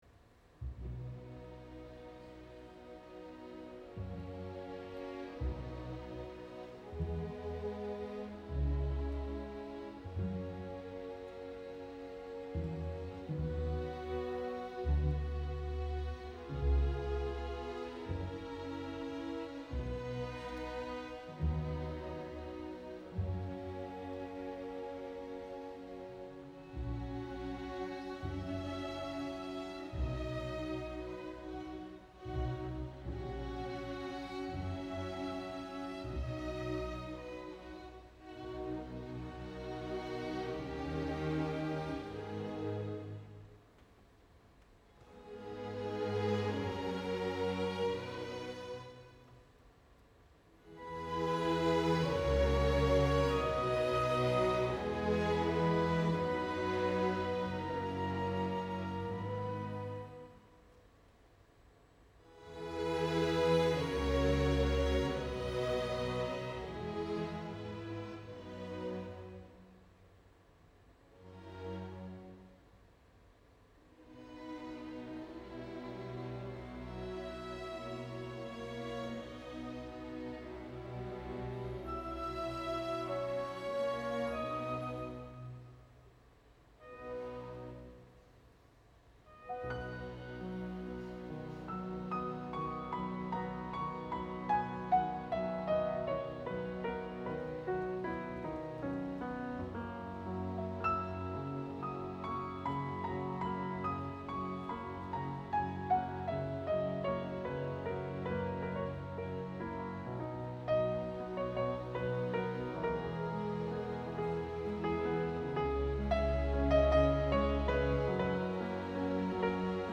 Вот кусочек этого же концерта с фортепиано и небольшой пост обработкой (реверочка немножко накинул) На Ф-но к сожалению только один микрофон удалось поставить, т.к рояль выкатывали только во 2м отделении и в впопыхах пришлось все делать. Рояль громковато, но я пока не занимался серьезно обработкой, все дорожки сырые.